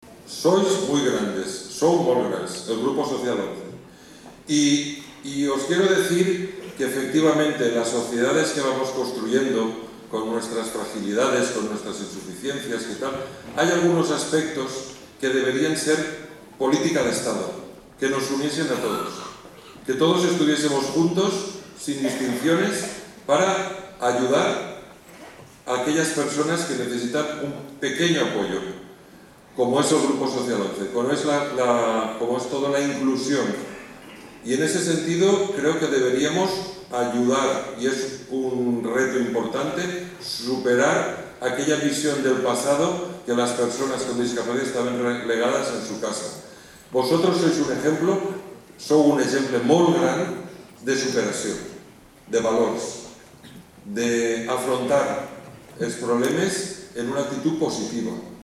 “Sois muy grandes”, reconocía el presidente de las Cortes Valencianas, Enric Morera, sobre ese escenario del Principal en el acto oficial de cierre de la Bienal.